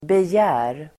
Uttal: [bej'ä:r]